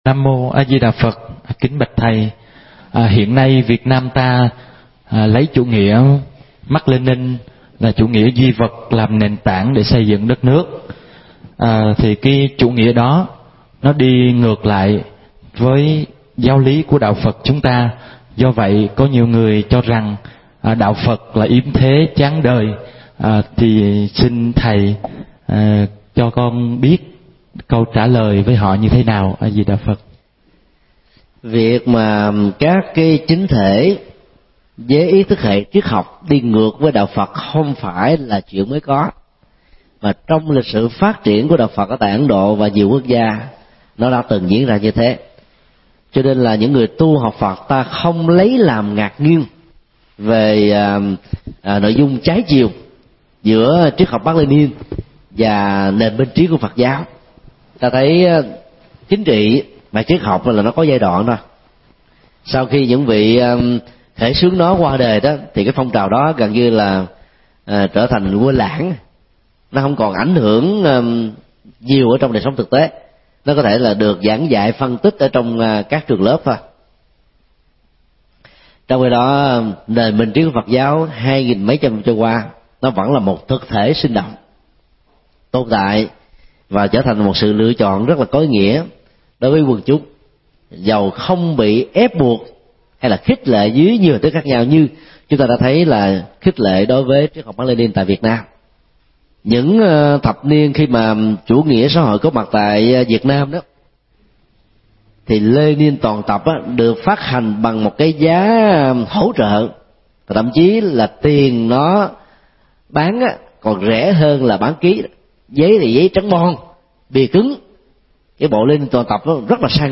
Vấn đáp: Triết học duy vật và Phật giáo – Thầy Thích Nhật Từ mp3